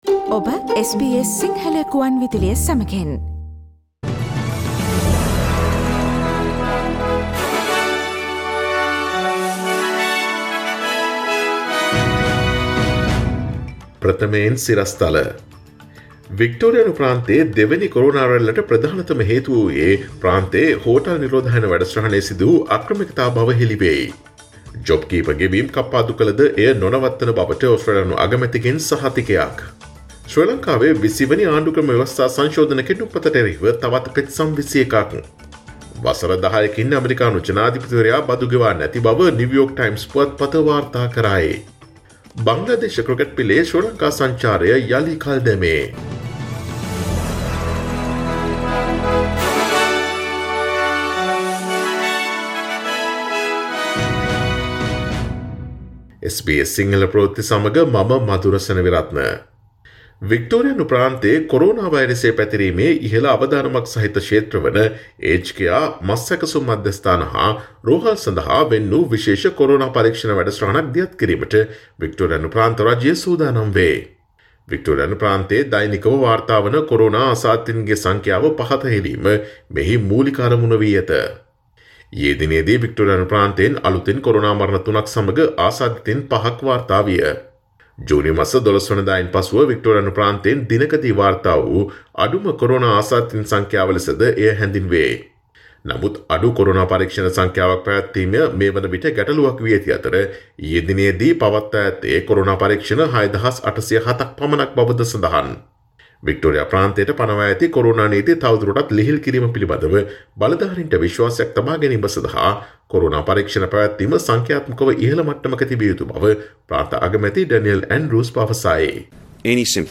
Daily News bulletin of SBS Sinhala Service: Tuesday 29 September 2020